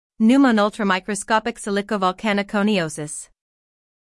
How to Pronounce Pneumonoultramicroscopicsilicovolcanoconiosis
🇺🇸 American:
Syllables: Pneu · mo · no · ul · tra · mi · cro · SCOP · ic · si · li · co · vol · ca · no · co · ni · O · sis
pneumonoultramicroscopicsilicovolcanoconiosis-us.mp3